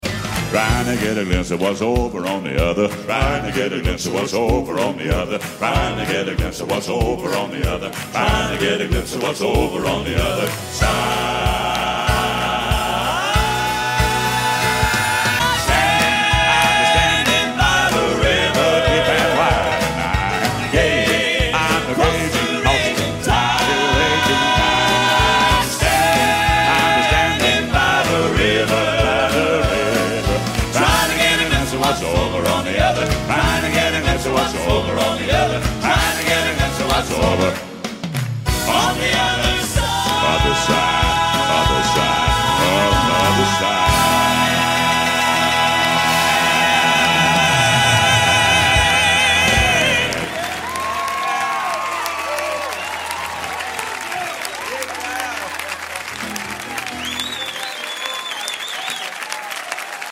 Gospel Quartet